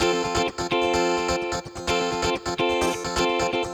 VEH3 Electric Guitar Kit 1 128BPM